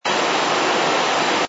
sfx_steam02.wav